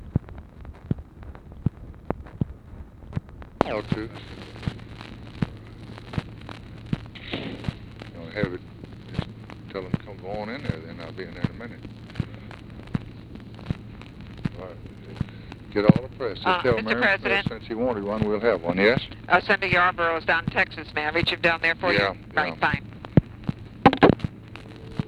OPERATOR PLACES CALL TO RALPH YARBOROUGH WHO IS IN TEXAS
Conversation with OFFICE CONVERSATION
Secret White House Tapes